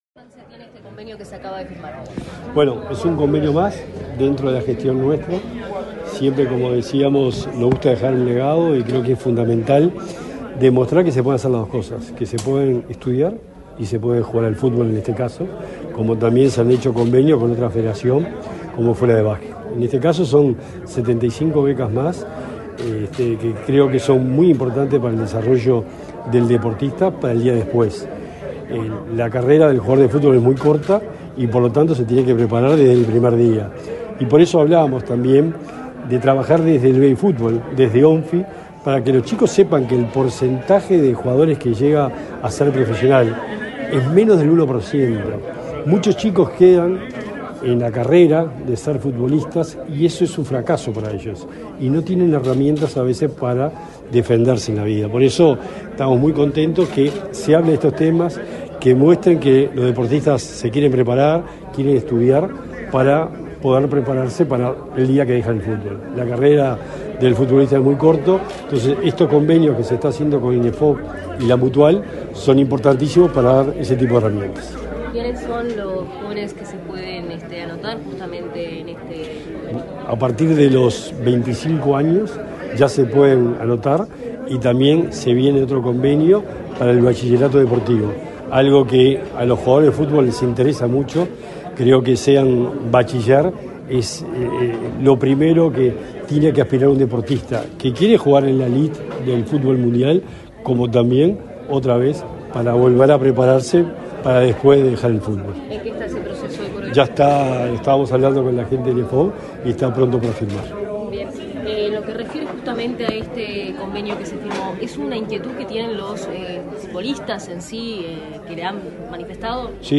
Declaraciones a la prensa del secretario del Deporte, Sebastián Bauzá
Tras participar en la firma de convenio entre el Instituto Nacional de Empleo y Formación Profesional, la Secretaría Nacional del Deporte y la Mutual Uruguaya de Futbolistas Profesionales, para apoyar la inserción laboral de los deportistas tras su tiempo competiciones oficiales, el secretario del Deporte, Sebastián Bauzá, realizó declaraciones a la prensa.